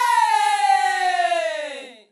Southside Vox (7).wav